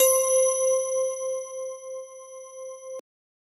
Bell 2.wav